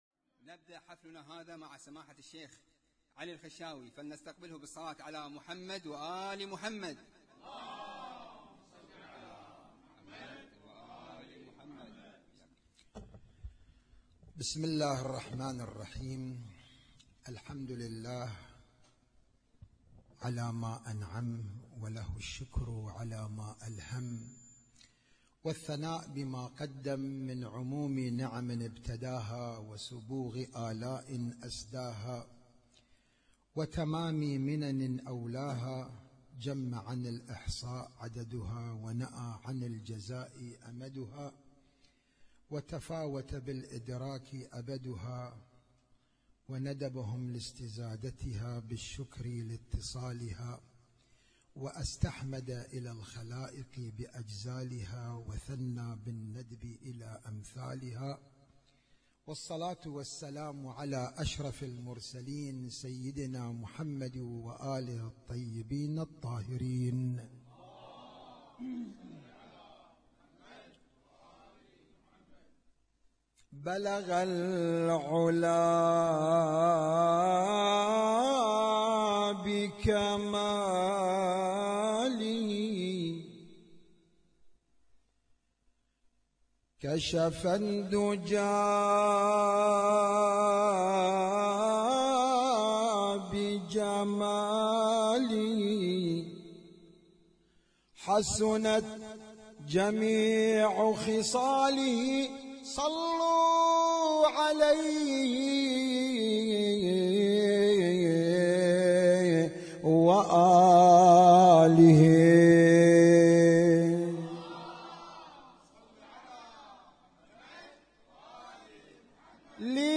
اسم النشيد:: مولد الامام المهدي عجل الله تعالى فرجه الشريف 1437
اسم التصنيف: المـكتبة الصــوتيه >> المواليد >> المواليد 1437